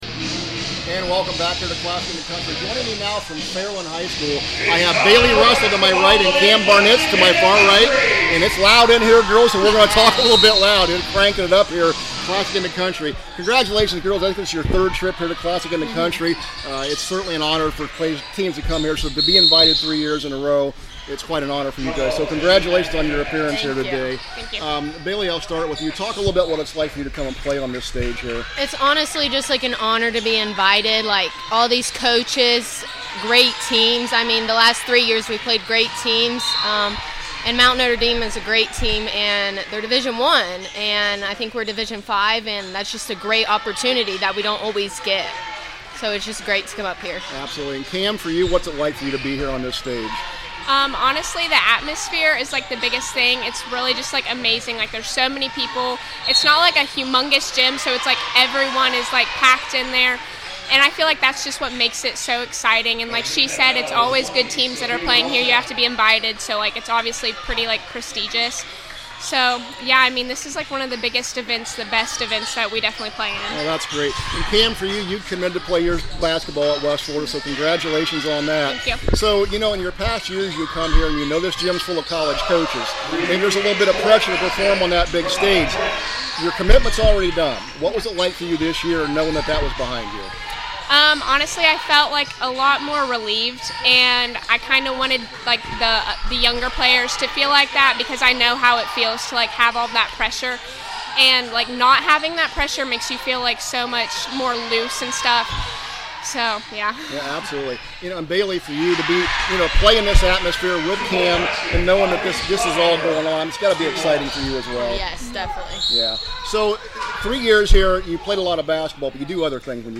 2025 Classic In the Country – Fairland Player Interviews